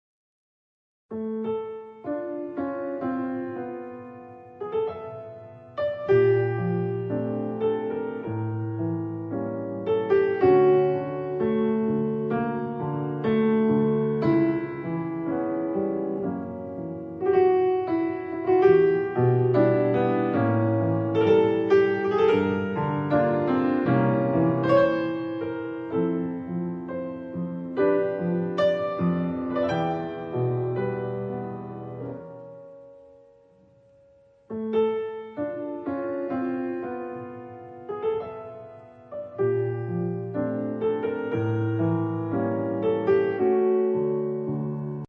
Klarinette und Klavier Obtížnost
Entrée Tango Milonga Tango